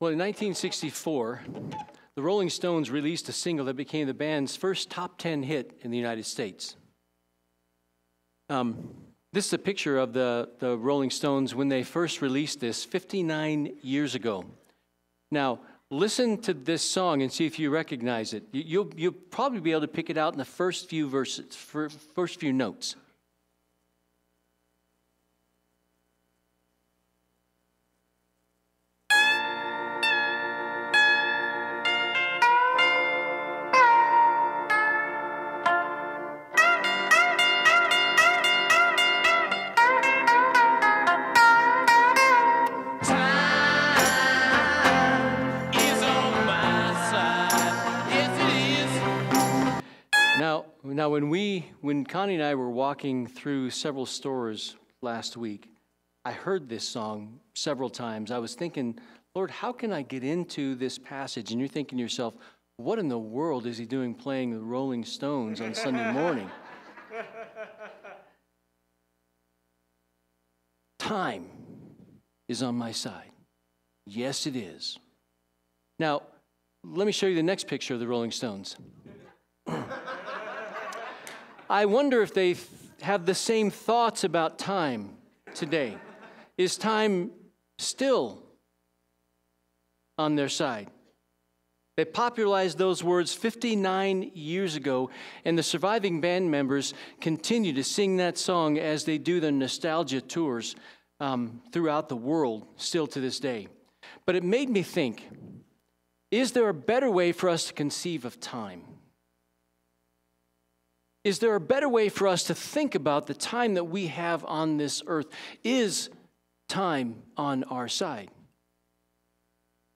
Sermons | First Baptist Church of Golden